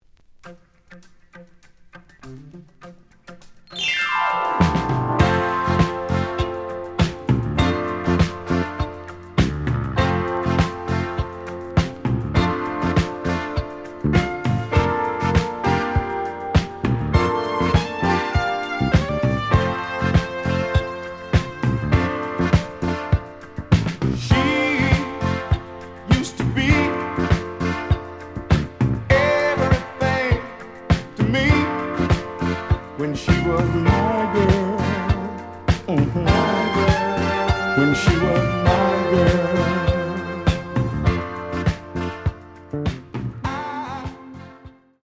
試聴は実際のレコードから録音しています。
●Genre: Soul, 80's / 90's Soul